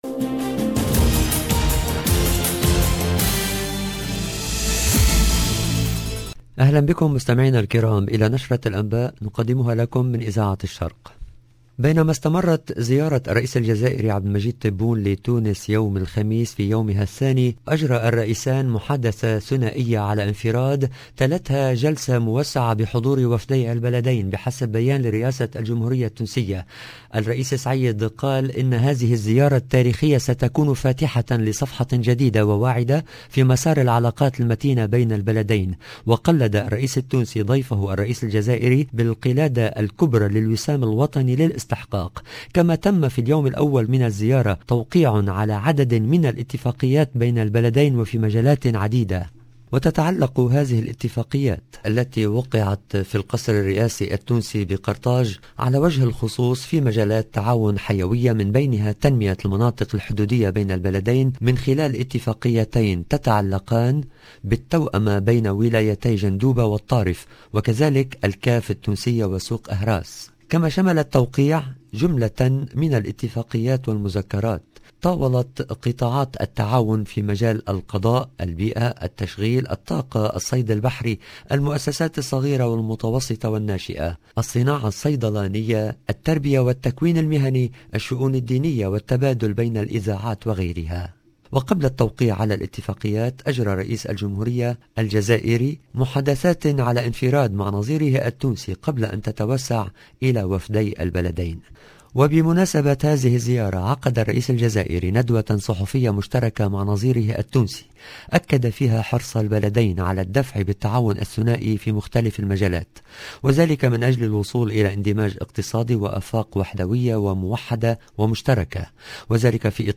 LE JOURNAL DU SOIR EN LANGUE ARABE DU 16/12/21